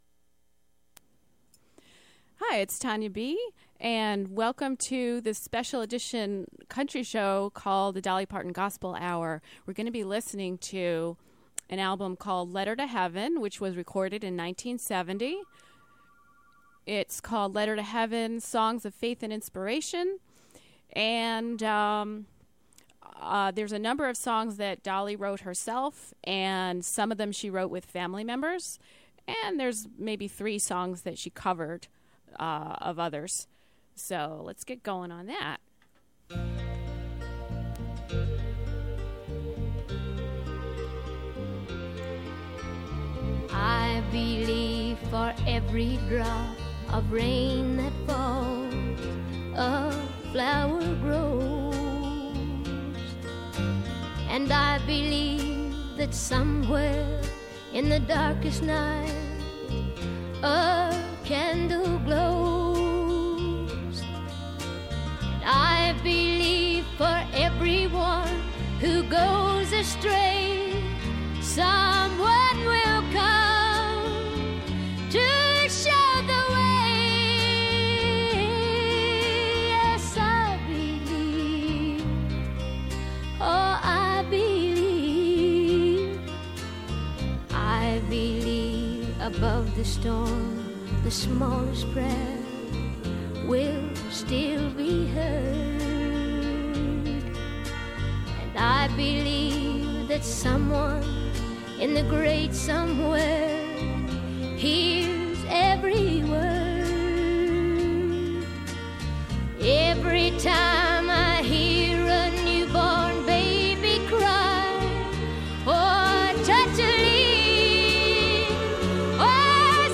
Classic Country Show